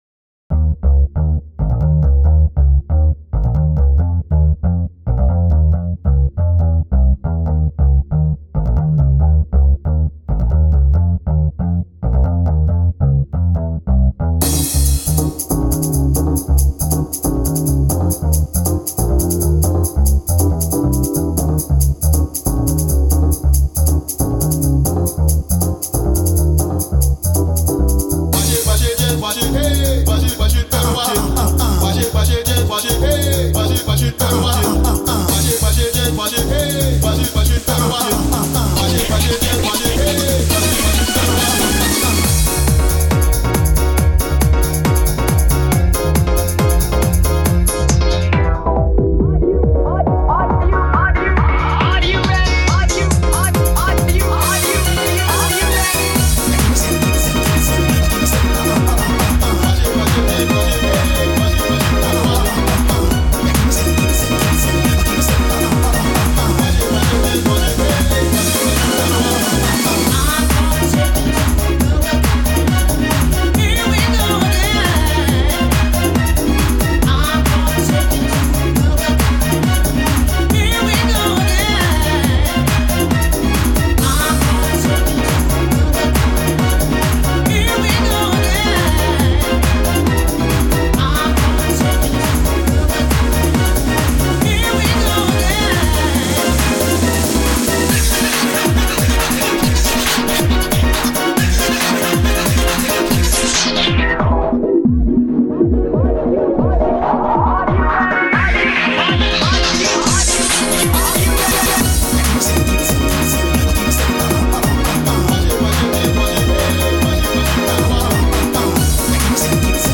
少し落ち着いた雰囲気のハウス系統はここで紹介させてもらうわね。
House